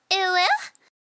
(без смеха)